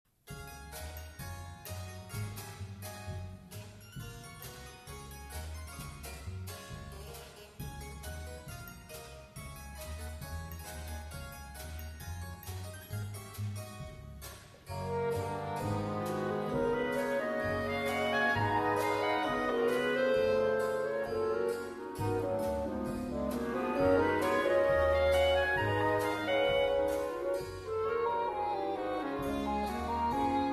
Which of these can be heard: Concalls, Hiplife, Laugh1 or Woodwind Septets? Woodwind Septets